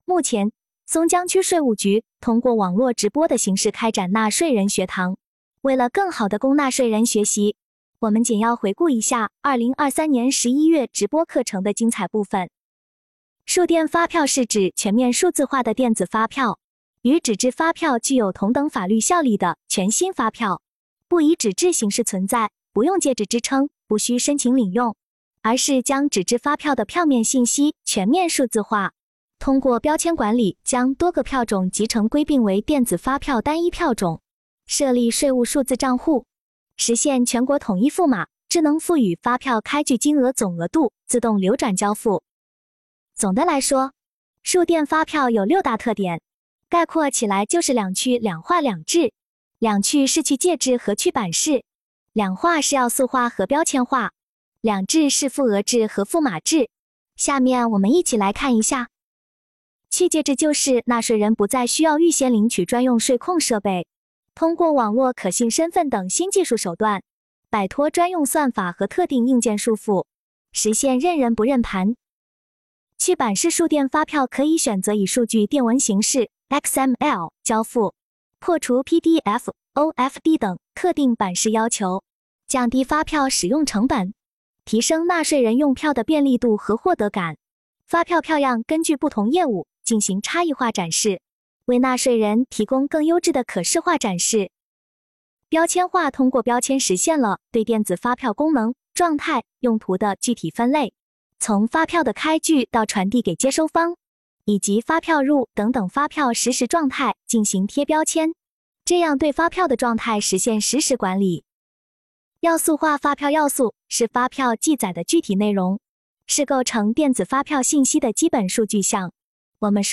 目前，松江区税务局通过网络直播的形式开展了纳税人学堂。